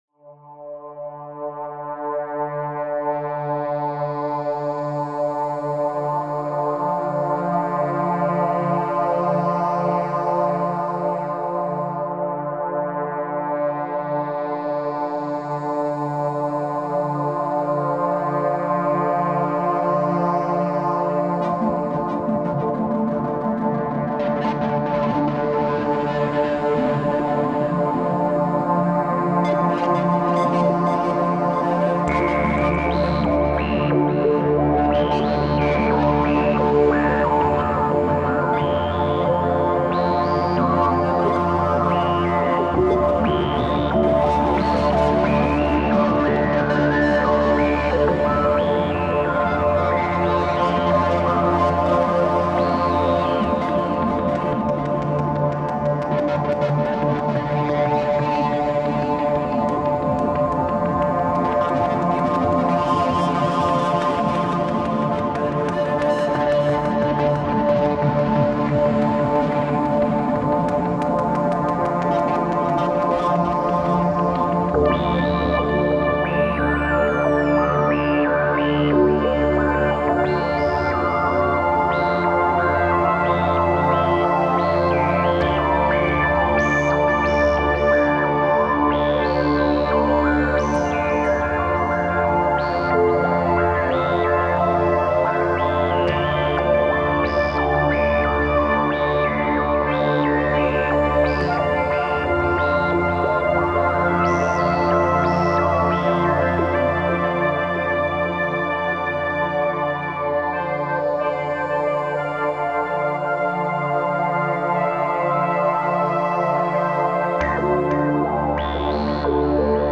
GENRE - Ambient - Space Oriented Synthesizer Instrumental